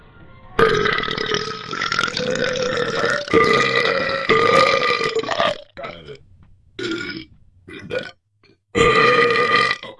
Звук отрыжки:
burping1.wav